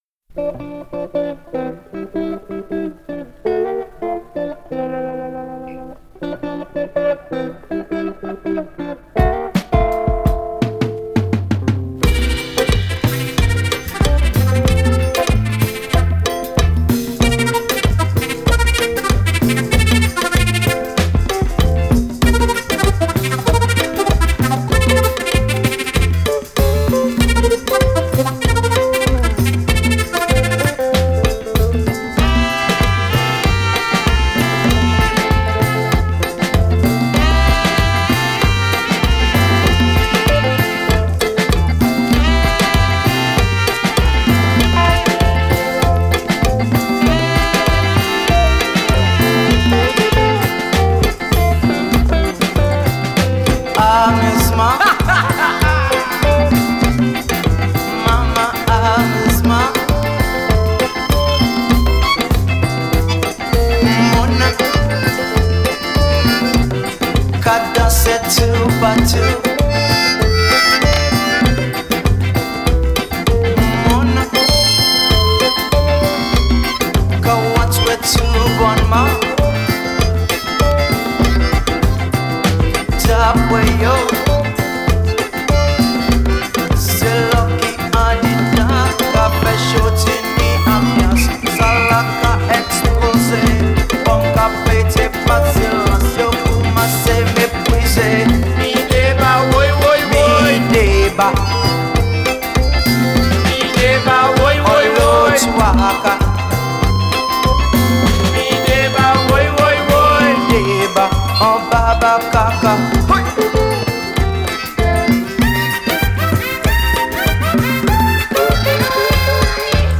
A tasty Compas and Cadence playlist